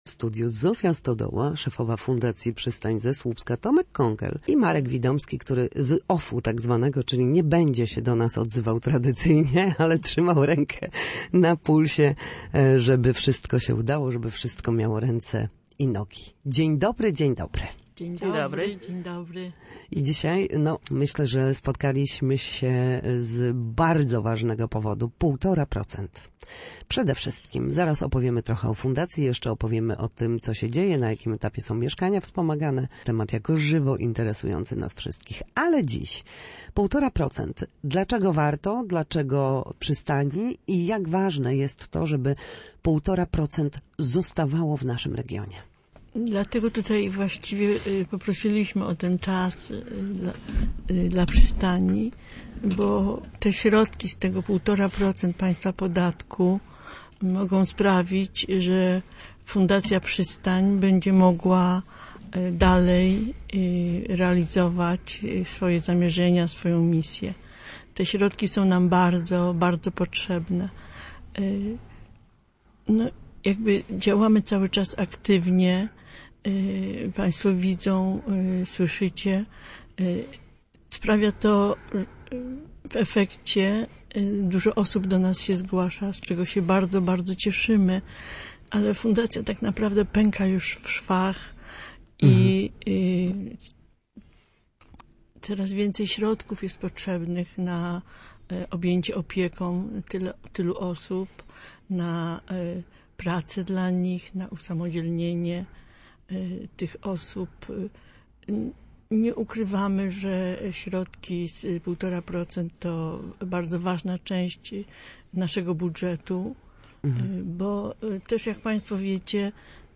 Na naszej antenie mówili o działaniach na rzecz podopiecznych, mieszkaniach wspomaganych, pracy osób z niepełną sprawnością intelektualną oraz o tym jak, ważne jest rozliczenie roczne z fiskusem.